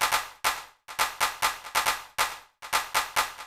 DELAY CLP -R.wav